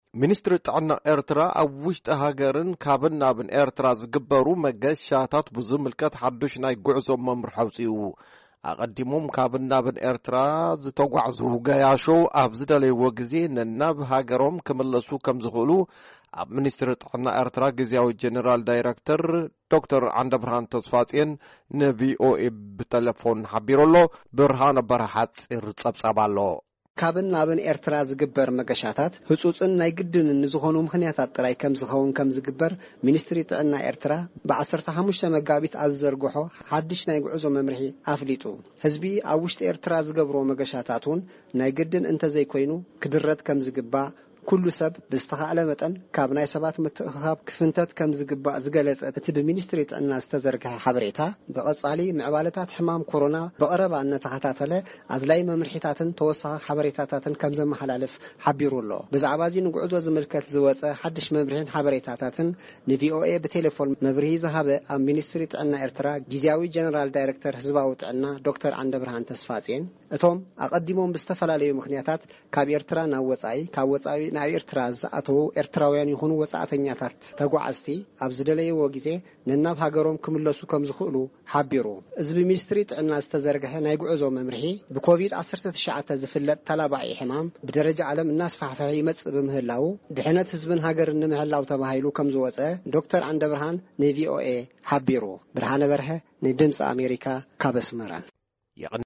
ኣቐዲሞም ካብን ናብን ኤርትራ ዝተጓዕዙ ገያሾ ኣብ ዝደለይዎ ግዜ ናብ ሃገሮም ክምለሱ ከምዝኽእሉ ኣብ ሚንስትሪ ጥዕና ኤርትራ ግዝያዊ ጀነራል ዳይረክተር ዶ/ ር ዓንደብርሃን ተስፋጽዮን ንድምጺ ኣሜሪካ ብተለፎን ሓቢሩ።